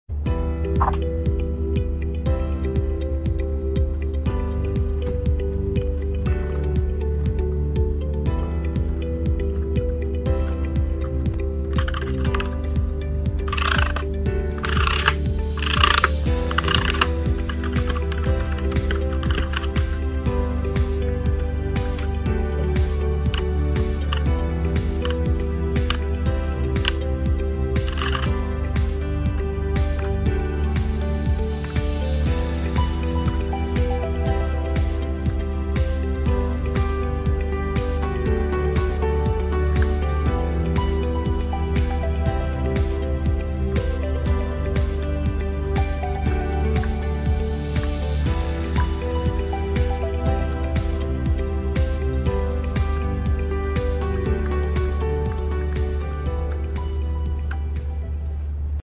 Royal Kludge RK87 80% Mechanical sound effects free download
Royal Kludge RK87 80% Mechanical Keyboard 87 keys Tenkeyless Compact layout suitable for home, office and gaming usage while connecting up to 3 devices via Bluetooth.